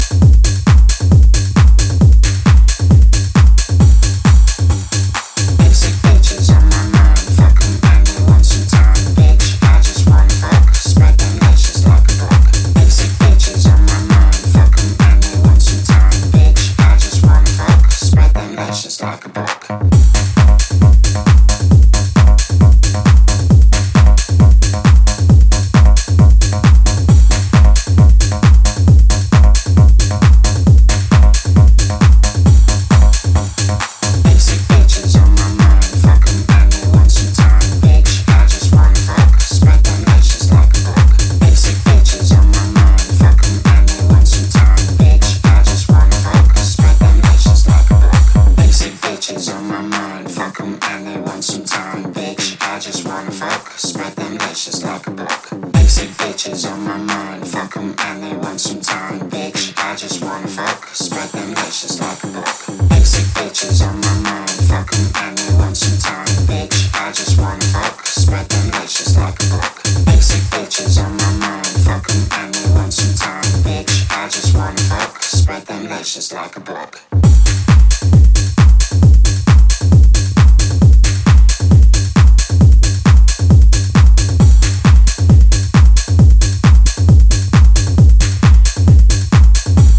pairing rapid kick-hat combos
layered vocal tones